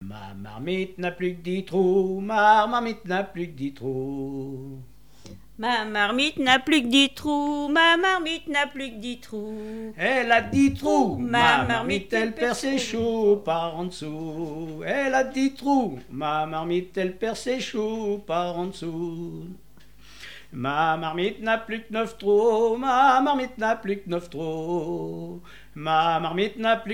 Mémoires et Patrimoines vivants - RaddO est une base de données d'archives iconographiques et sonores.
branle
Couplets à danser
danse : ronde : demi-rond
Pièce musicale inédite